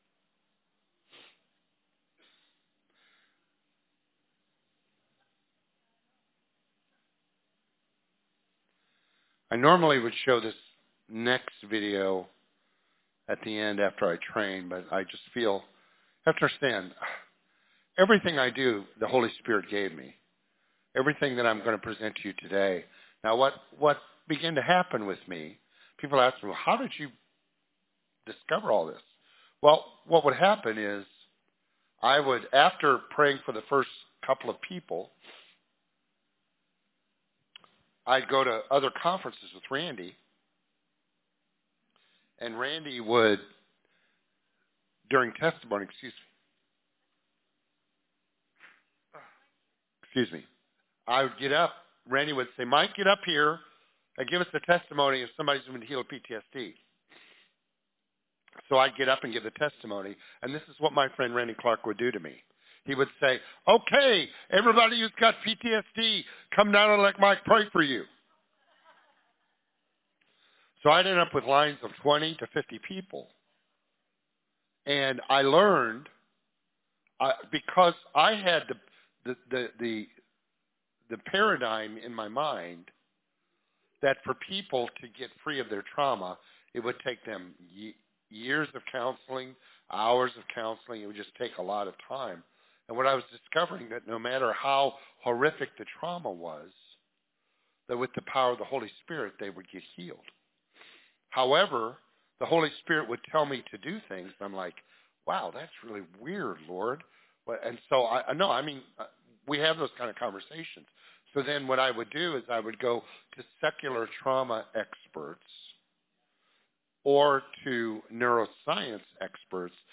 Grace House Florence AL | God Heals PTSD | Faith-Based Trauma Recovery Sermon Series